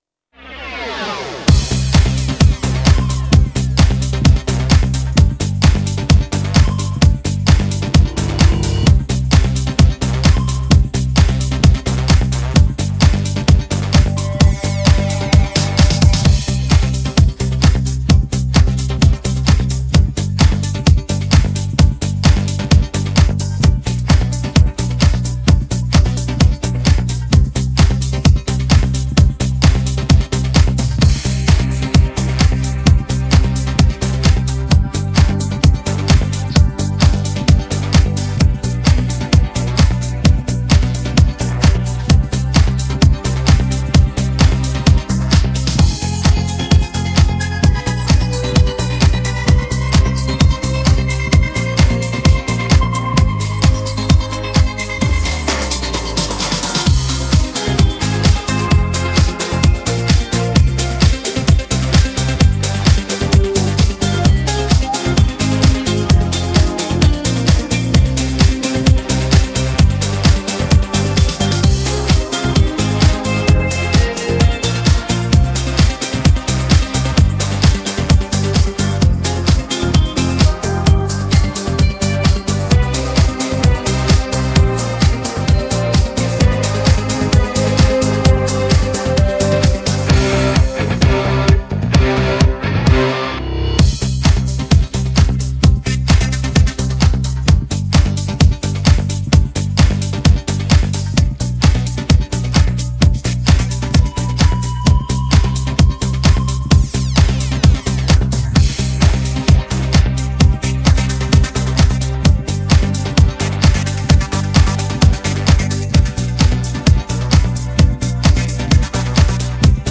российская хип-хоп группа
минус